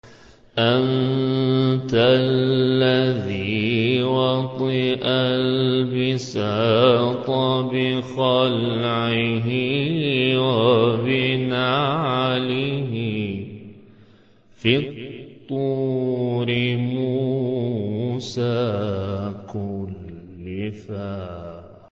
حجاز-اصلی-قرار2.mp3